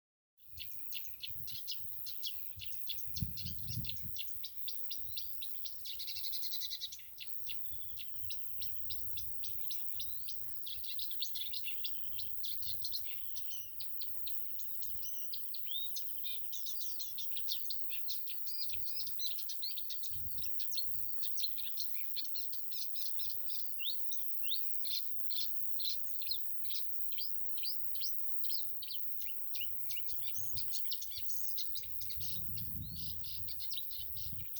Marsh Warbler, Acrocephalus palustris
Count2
StatusPermanent territory presumed through registration of territorial behaviour (song, etc.)